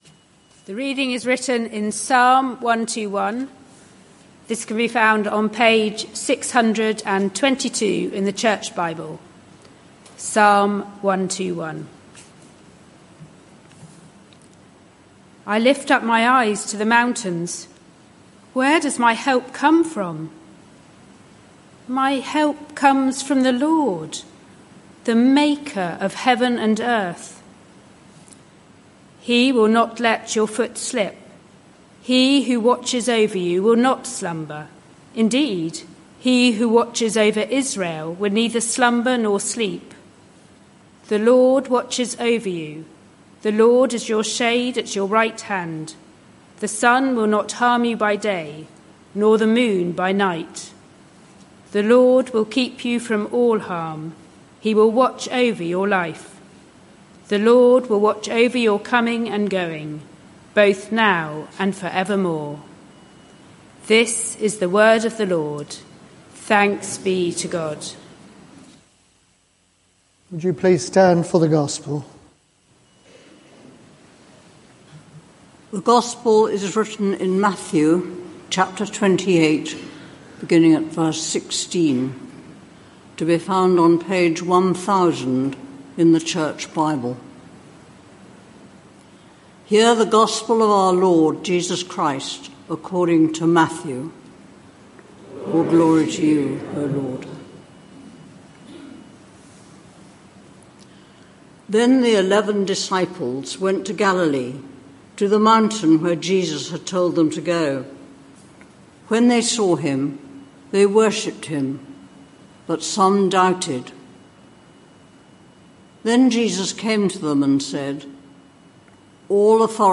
This sermon is part of a series: 12 January 2025